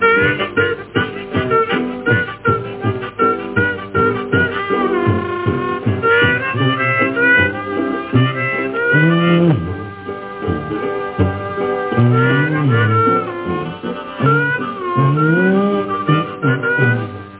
банджо, джаг
гармоника
гитара